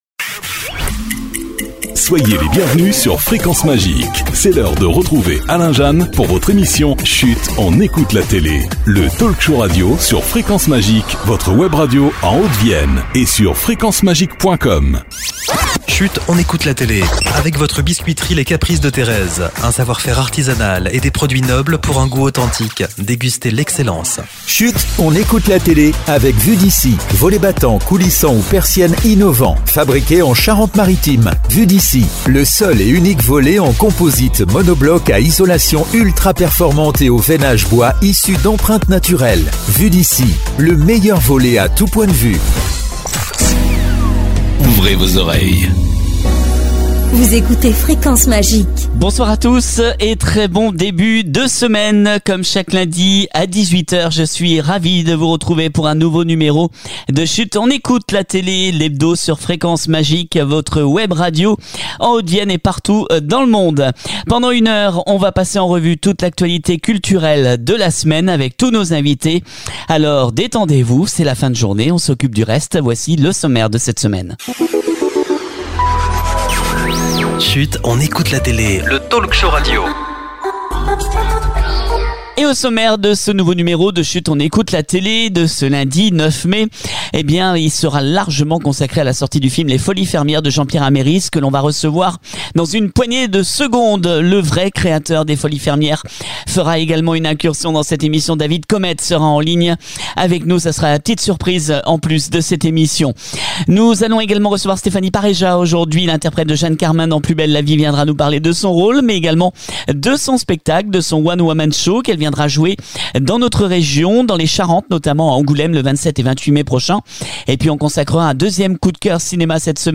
le premier les folies fermières le nouveau film de Jean Pierre Améris qui était notre invité exceptionnel